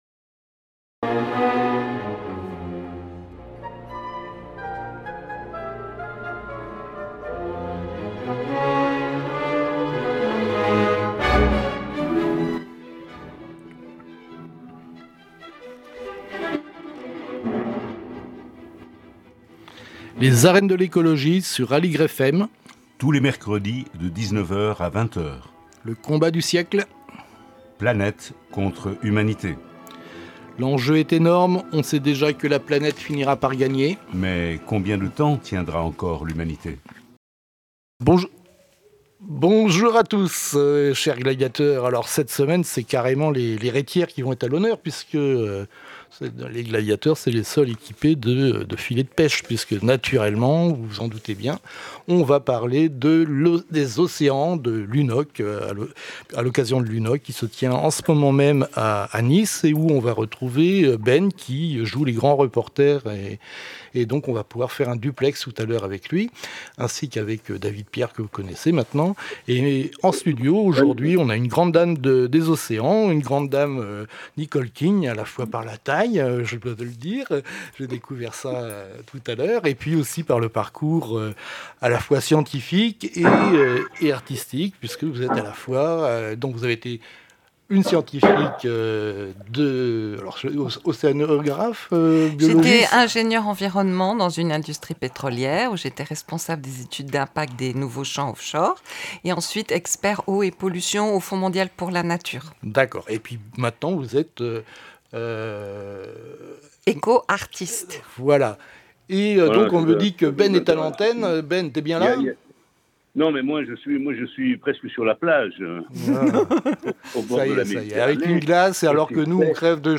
Les arènes de l'écologie, tous les mercredis de 19:00 à 20:00 sur Aligre FM.